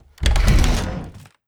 rust-rl/resources/sounds/door/open1.wav at e3a8c545fb977e576db3ea4e4022c8902ab4f3a5
door sounds